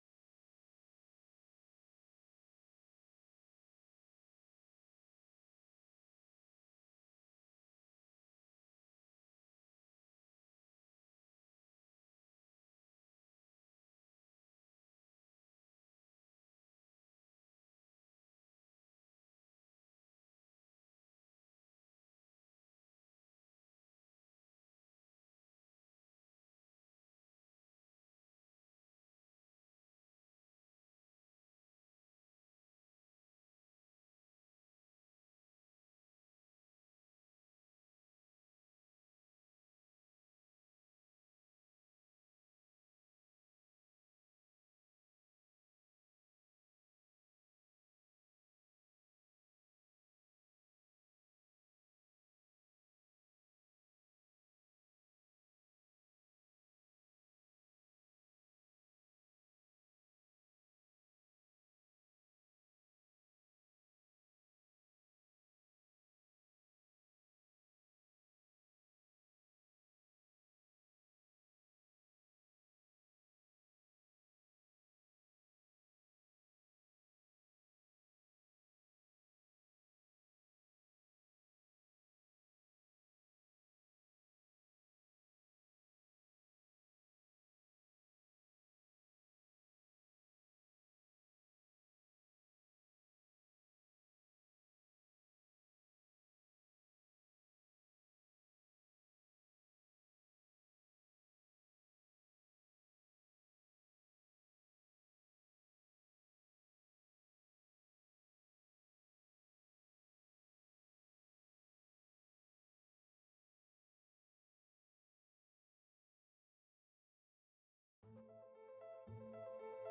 Weihnachtsgottesdienst 2025 - Gott mit uns ~ Predigt-Podcast von unterwegs FeG Mönchengladbach Podcast